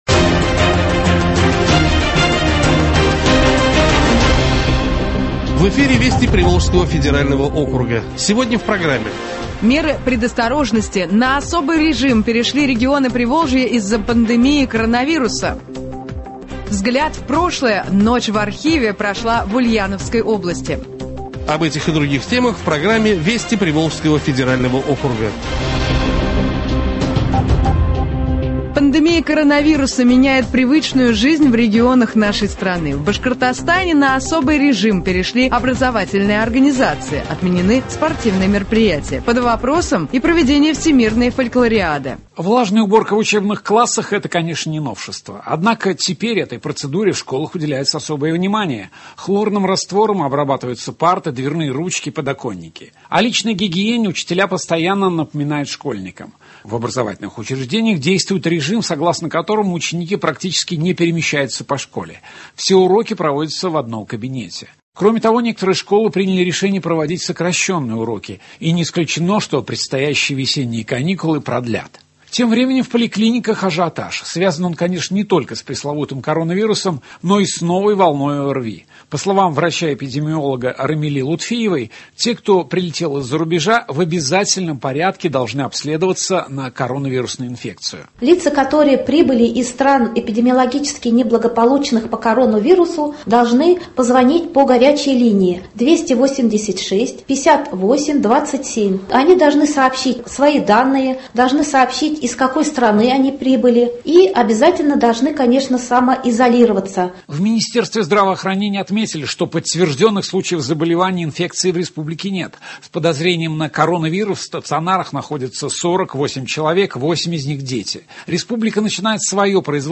Радиоверсия телепрограммы, рассказывающей о событиях в Приволжском федеральном округе.